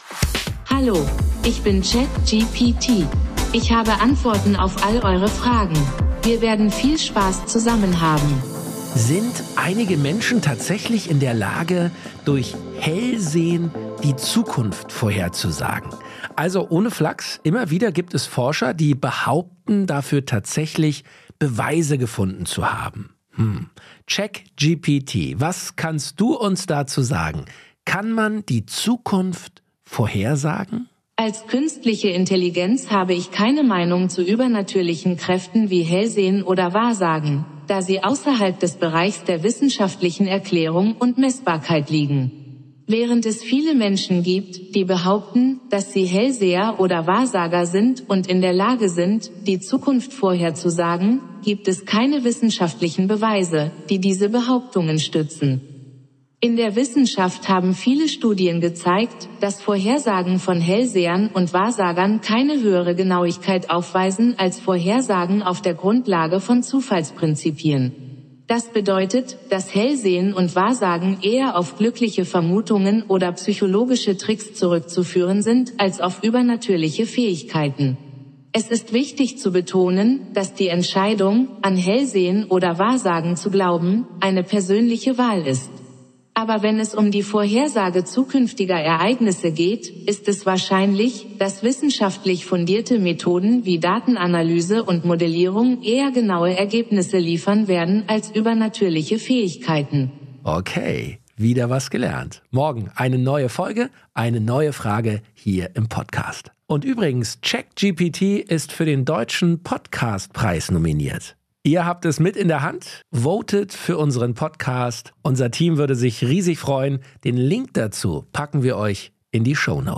Künstlichen Intelligenz ChatGPT von OpenAI als Co-Host.